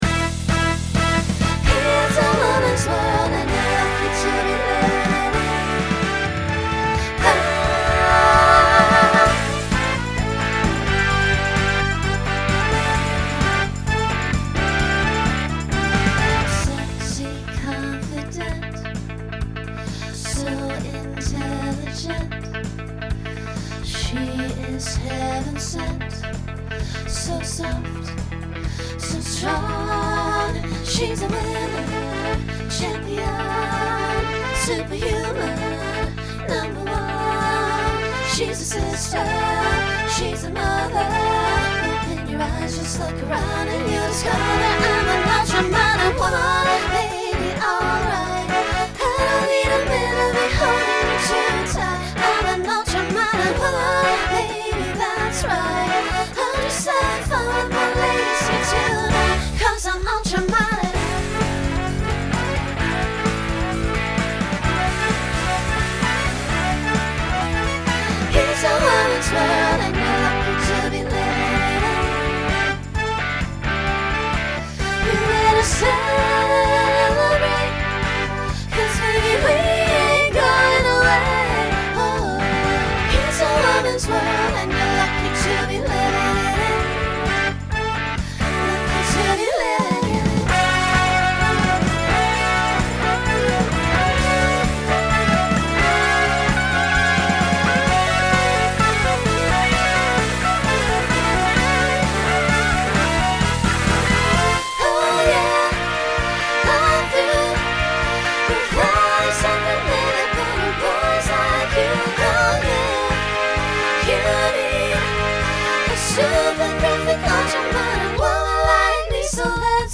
Pop/Dance
Voicing SSA